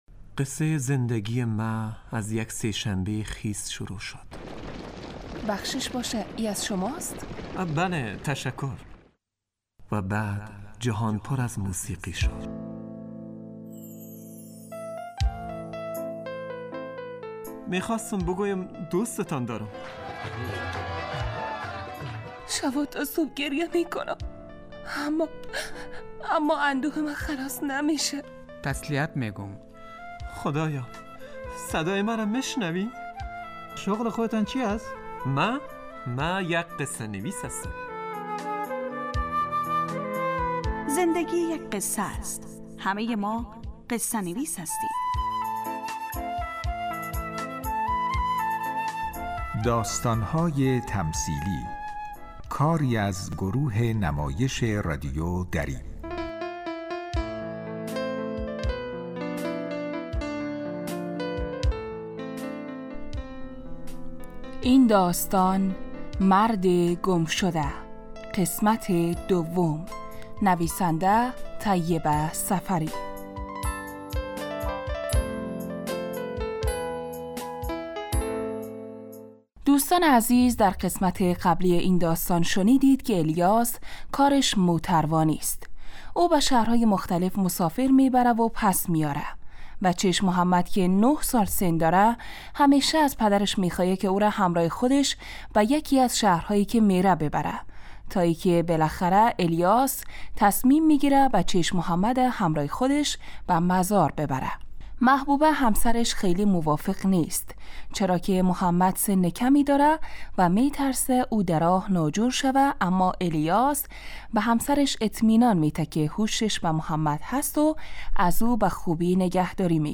داستانهای تمثیلی نمایش 15 دقیقه ای هستند که هر روز ساعت 4:45 عصربه وقت وافغانستان پخش می شود.